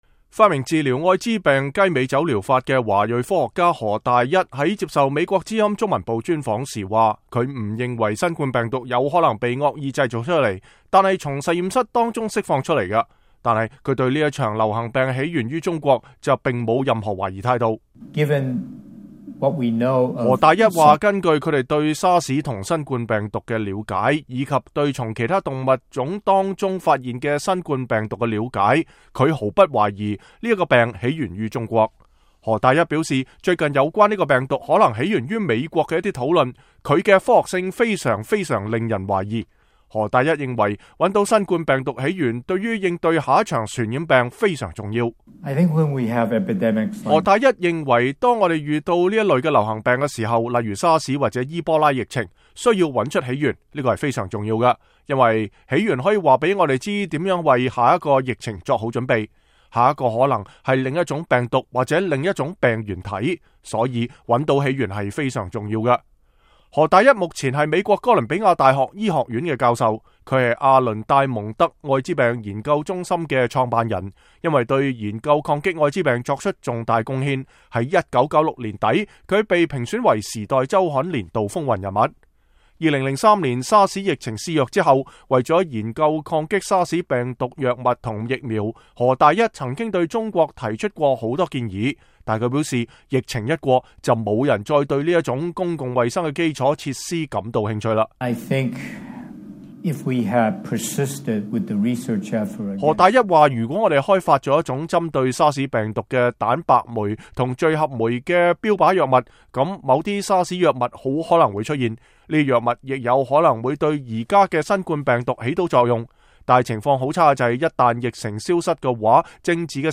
華裔科學家何大一正領導哥倫比亞大學4個團隊研發抗新冠病毒的藥物。他在接受美國之音專訪時說，人類在20年時間裡遭遇3次冠狀病毒來襲（SARS、MERS、COVID-19）。